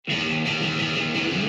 a noisy, punky, mathy 3-piece